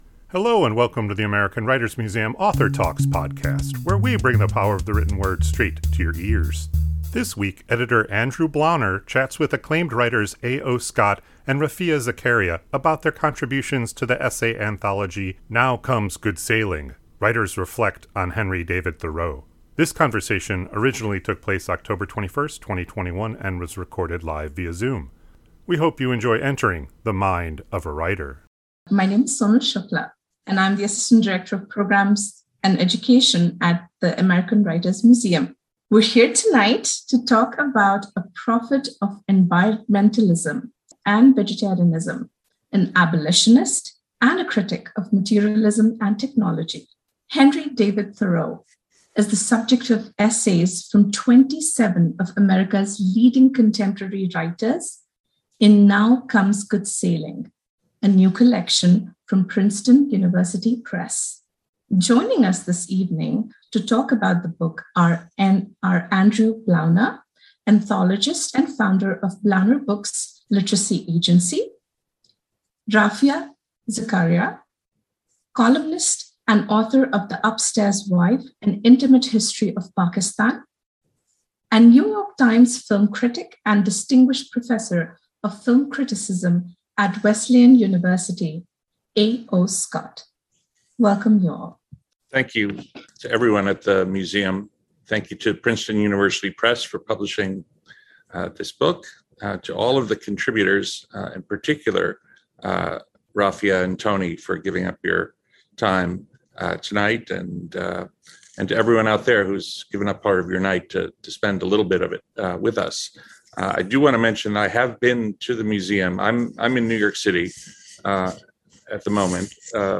This conversation originally took place October 21st, 2021 and was recorded live via Zoom.